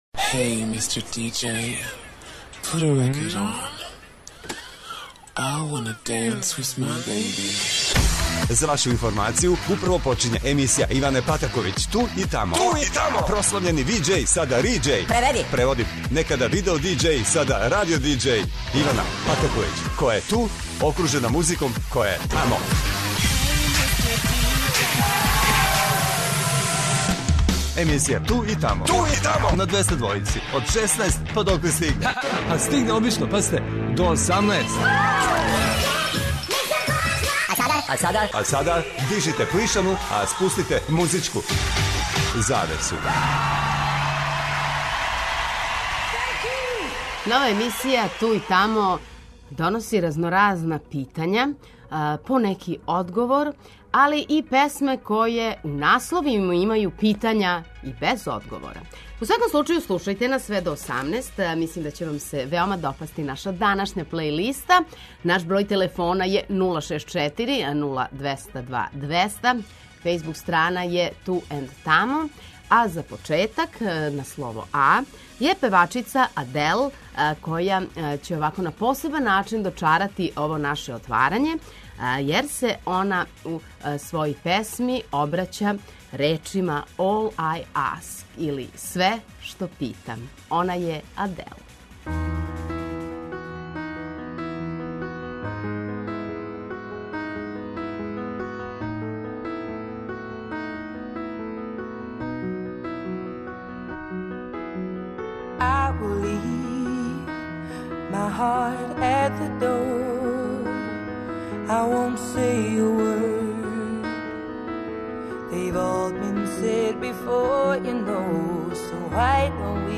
Значи имамо питања, имаћемо и неке одговоре, а завртеће се и хитови који постављају питања без одговора.